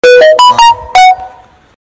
EARAPE ANDROID BEEP
earape-android-beep.mp3